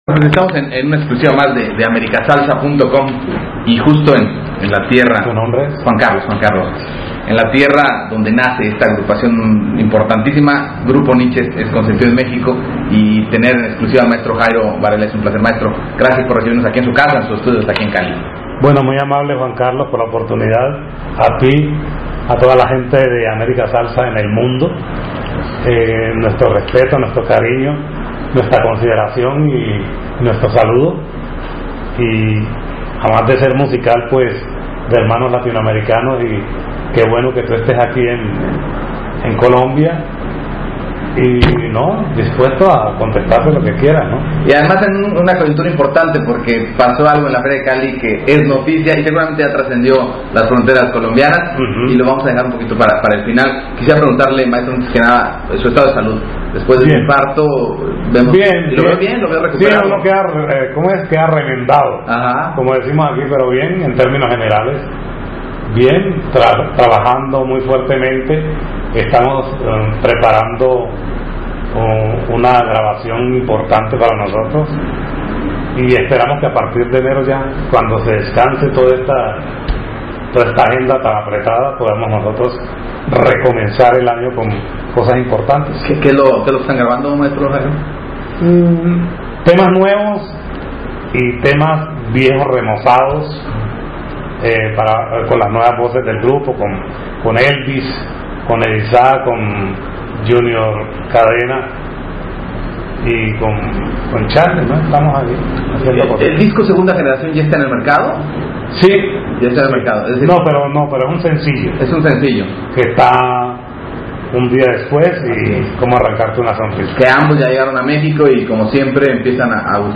Jairo Varela entrevista con America Salsa
AUDIO DE LA ENTREVISTA -El Tributo a la Salsa Colombiana de Alberto Barros es una copia y las segundas partes siempre son malas, afirma.